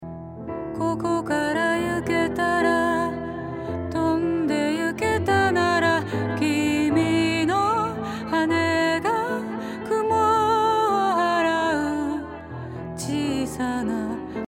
ディレイの重要なパラメーターの一つに「Feedback」があります。
少し多めだとこんな感じです。